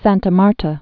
(săntə märtə, säntä märtä)